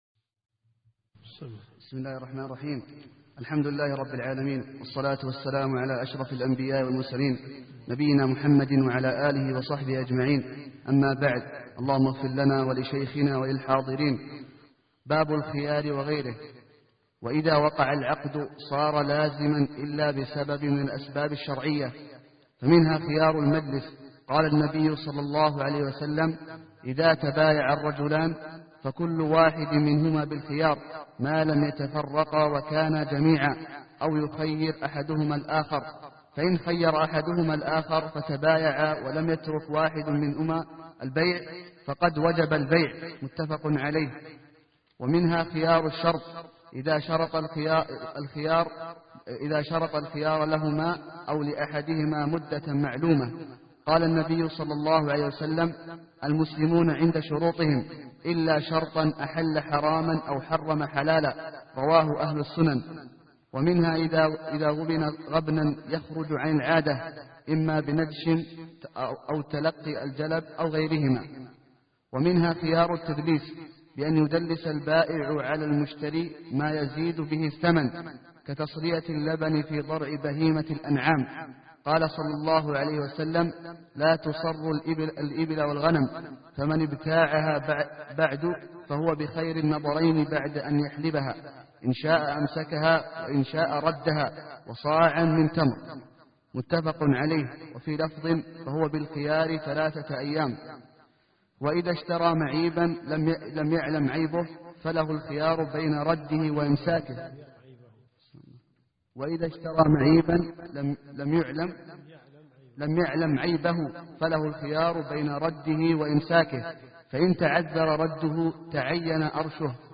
الدروس الشرعية
منهج السالكين . كتاب البيوع . من ص 64 باب الخيار -إلى- ص 68 قوله وهو الأقل من أجره مثله أو كفايته . المدينة المنورة . جامع البلوي